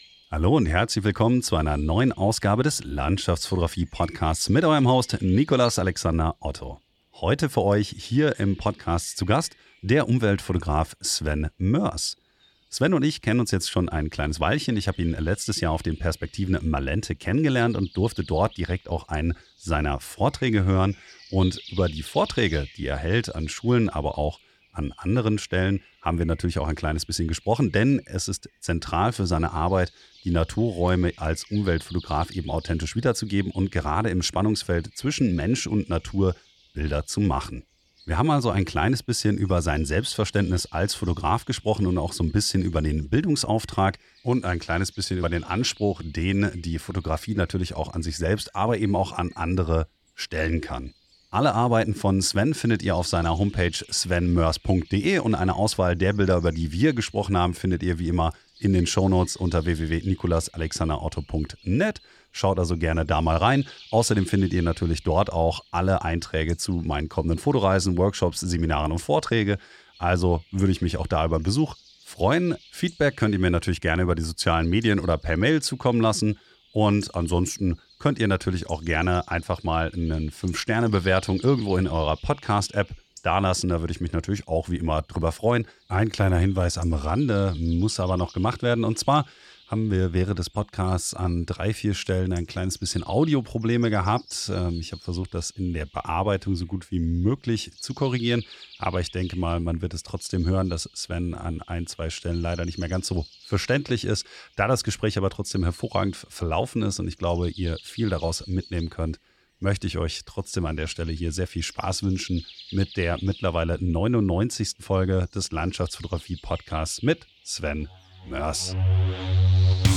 In der ersten Hälfte des Podcasts hatten wir einige Audioprobleme, welche sich leider in der Bearbeitung nicht ohne weiteres beheben ließen.